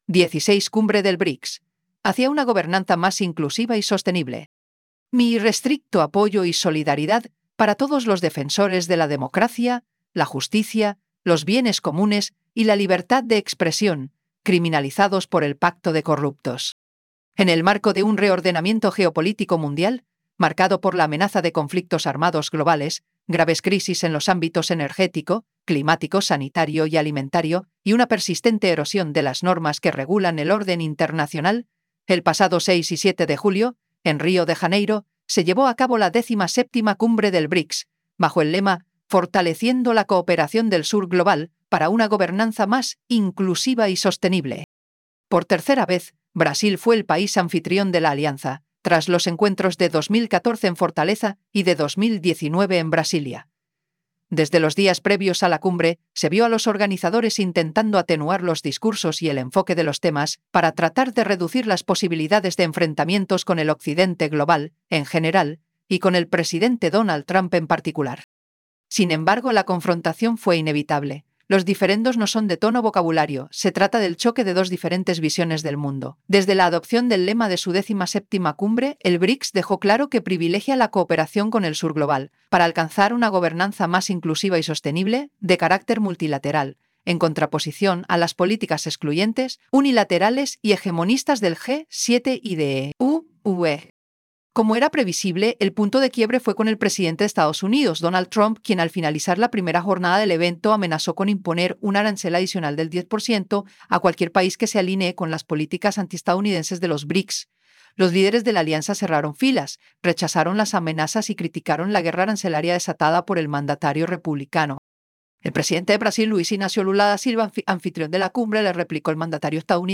PlayAI_XVII_Cumbre_del_BRICS_hacia_una.wav